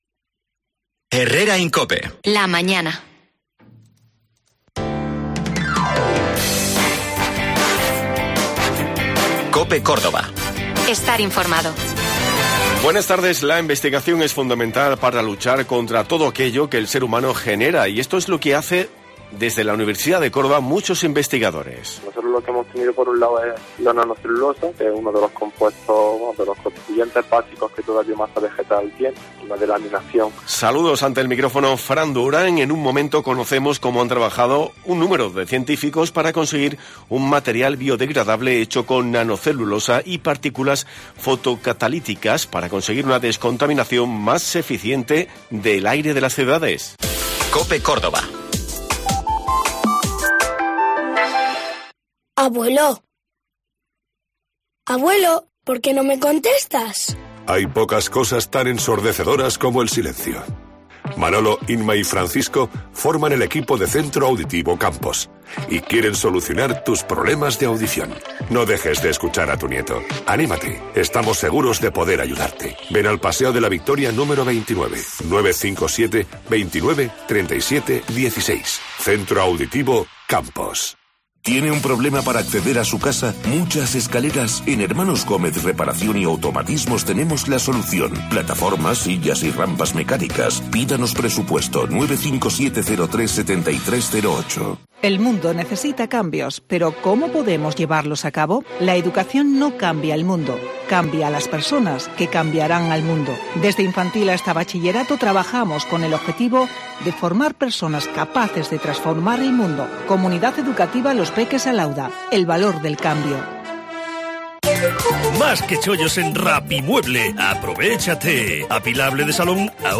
Hoy hemos hablado con uno de los investigadores de la Universidad de Córdoba porque han logrado descontaminar el aire a partir de residuos agrícolas.